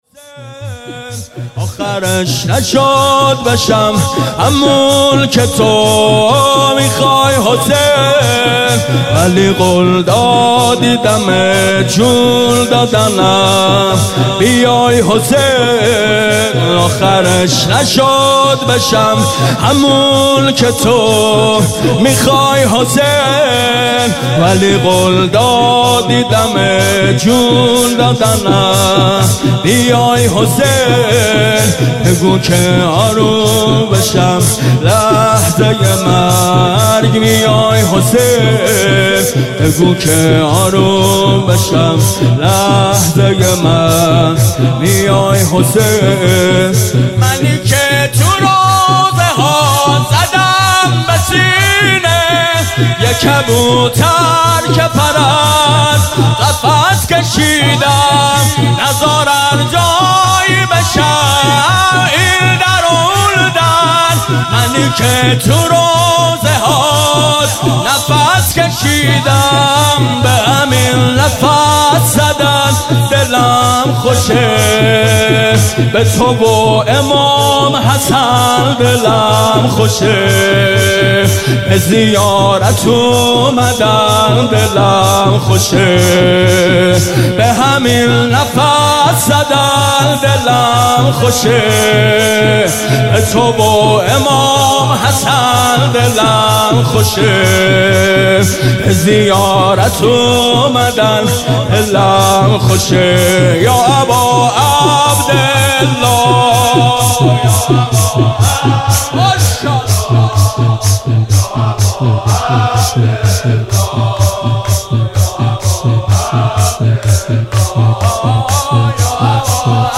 عنوان شب چهاردهم ماه مبارک رمضان ۱۳۹۸
شور آخرش نشد بشم همونکه تو میخوای حسین